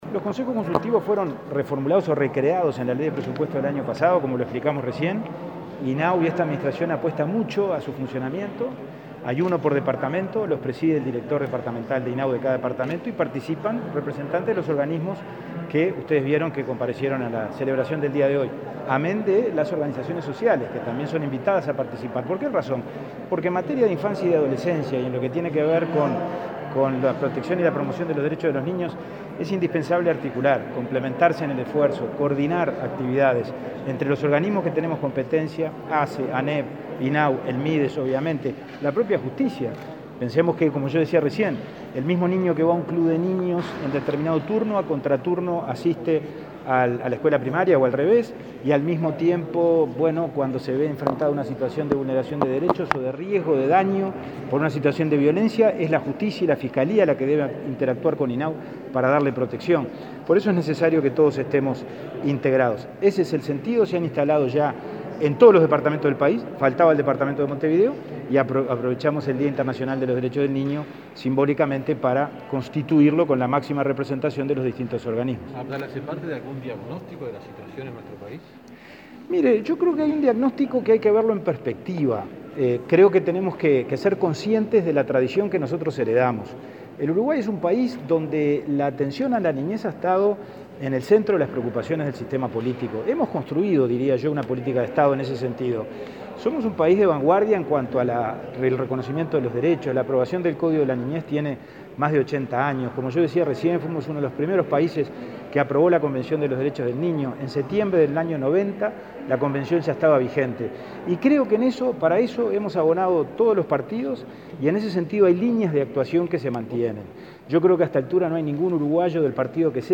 Declaraciones del presidente de INAU, Pablo Abdala, a la prensa
Declaraciones del presidente de INAU, Pablo Abdala, a la prensa 19/11/2021 Compartir Facebook X Copiar enlace WhatsApp LinkedIn El presidente deI Instituto del Niño y Adolescente del Uruguay (INAU), Pablo Abdala, participó este viernes 19 en Montevideo en la instalación del Consejo Consultivo Honorario de los Derechos del Niño y el Adolescente y, luego, dialogó con la prensa.